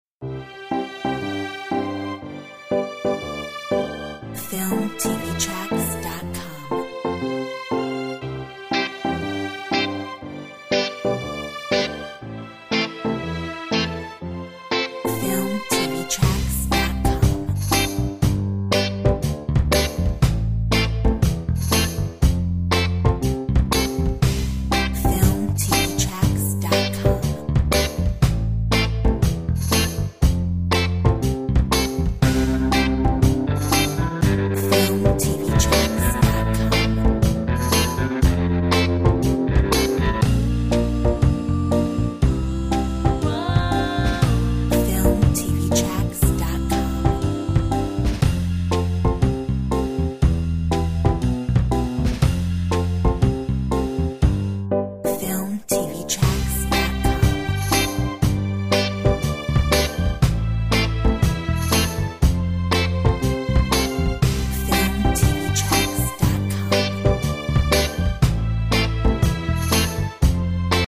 Corporate , POP